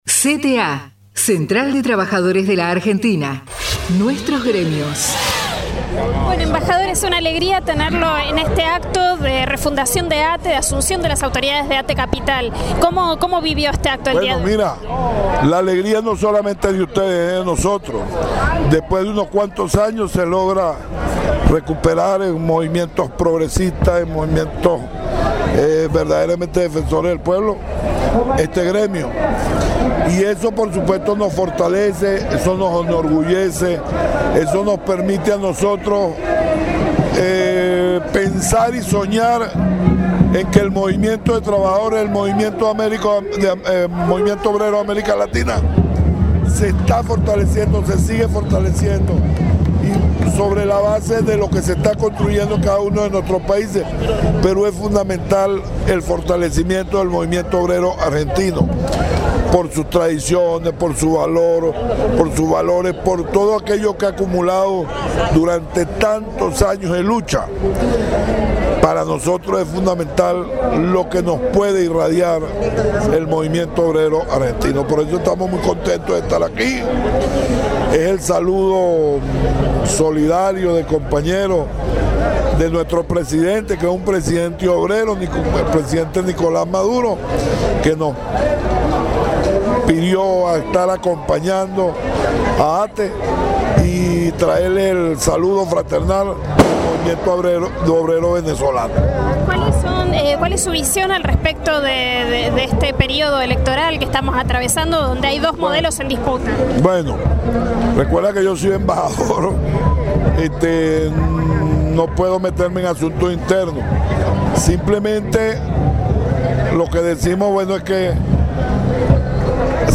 ATE CAPITAL FEDERAL - ACTO ASUNCIÓN NUEVAS AUTORIDADES
ate_capital_embajador_de_venezuela.mp3